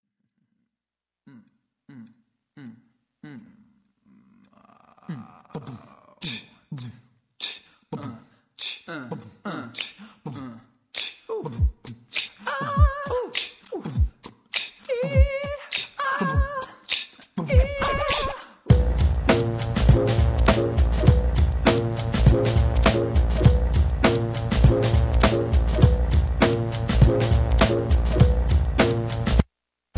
rock, r and b, rap